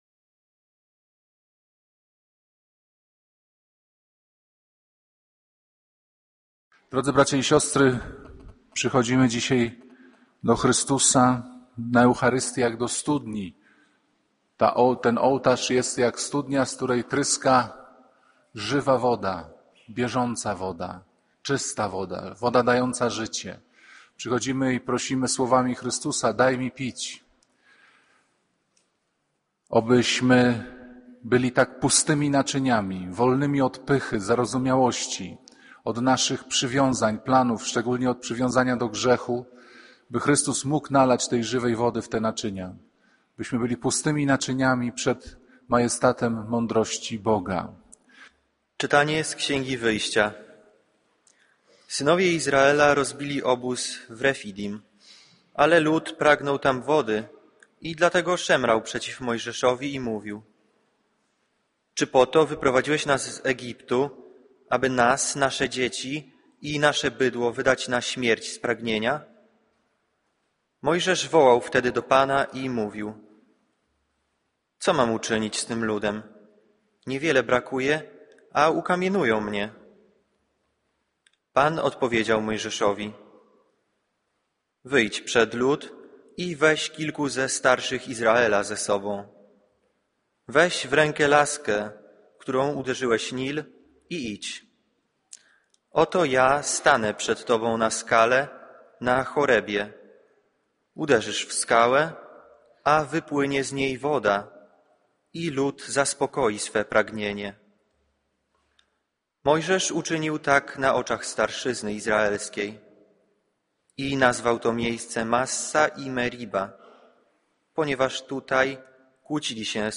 Ryzykowna i łamiąca obyczaje rozmowa Jezusa z kobietą – Kazania ks Piotra Pawlukiewicza
To jedno z najbardziej przejmujących i intensywnych kazań księdza Piotra Pawlukiewicza. Z charakterystyczną szczerością i duchową głębią opowiada historię św. Pawła – ale nie jako wydarzenie sprzed wieków, tylko jako model duchowego przewrotu, który może przydarzyć się każdemu z nas.